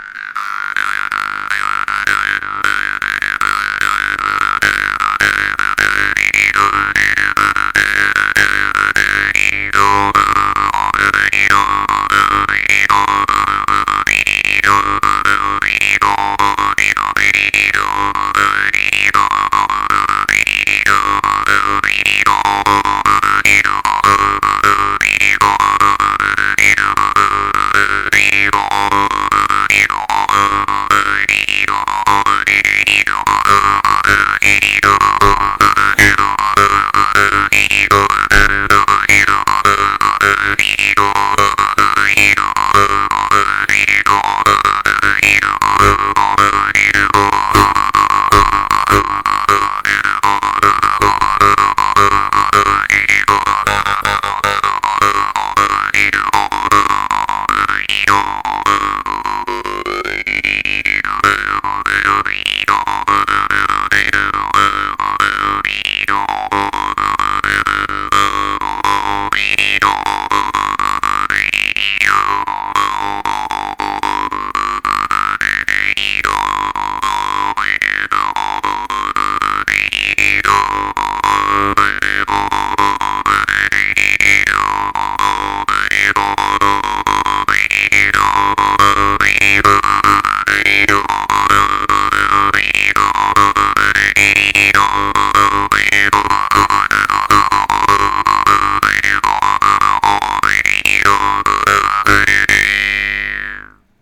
Русский варган
Комментарий: Мягкий язычок, долгое затухание, изящный и прочный корпус. Очень приятный и насыщенный звук, гармоничный набор обертонов.
Послушать: mp3: Размеренная игра
Двусторонний бой + толчки диафрагмой
Двусторонний бой + различная артикуляция